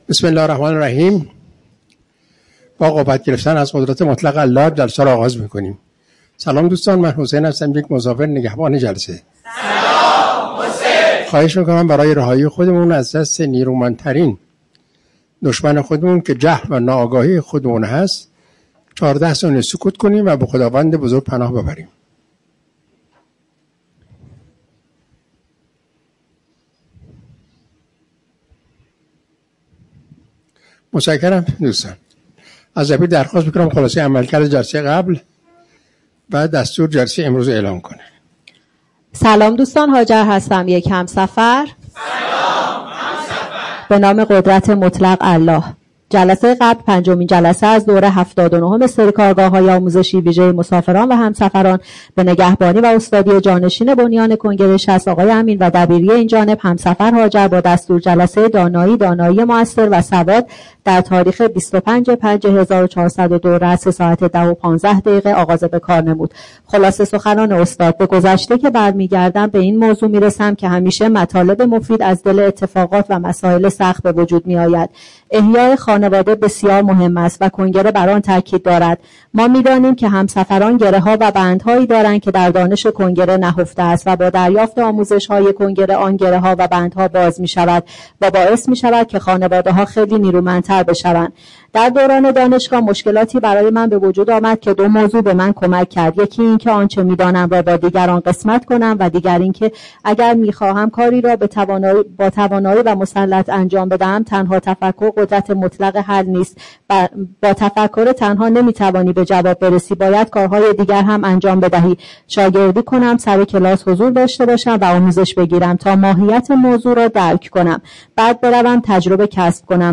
کارگاه آموزشی جهان‌بینی؛ هفته ایجنت و مرزبان